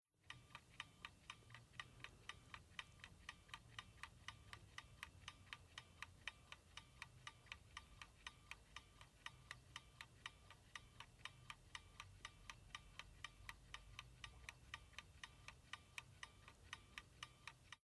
Kitchen wall clock with built-in timer "Exacta"
Ticking, winding, ringtone
ticking-winding-ringtone.mp3